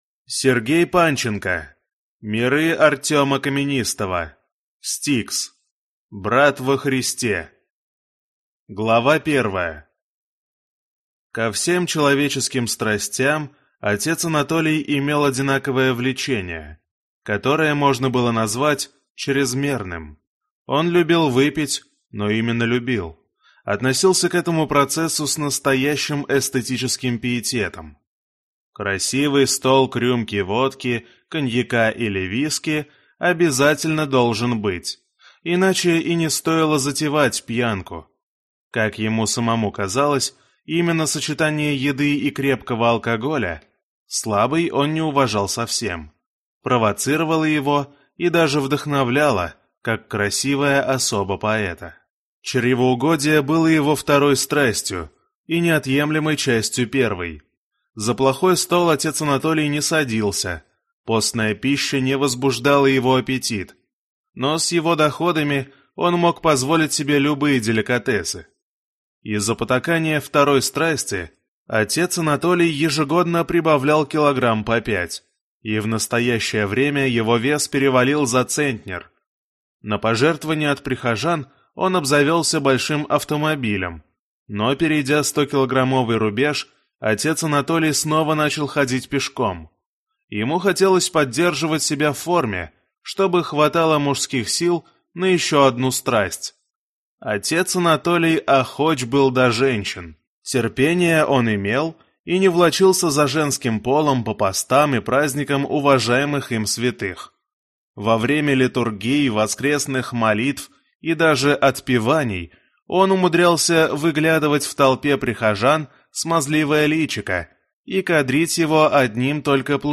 Аудиокнига S-T-I-K-S. Брат во Христе | Библиотека аудиокниг